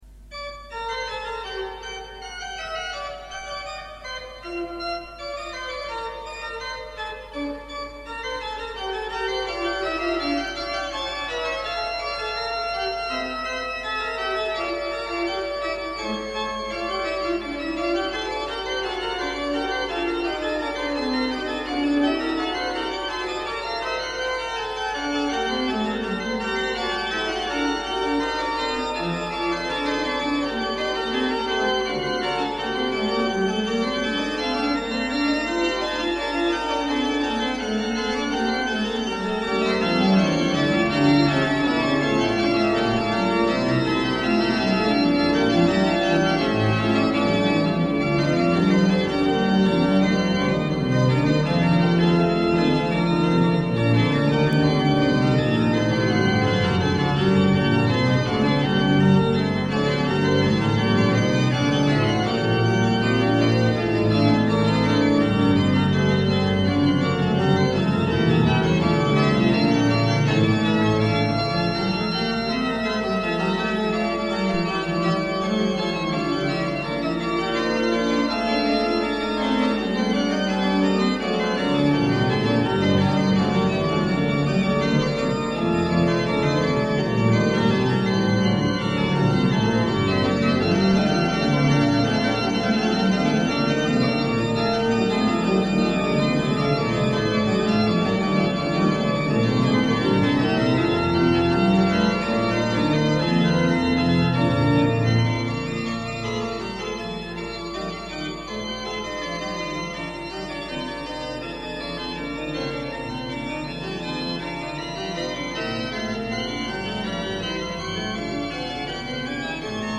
Órgano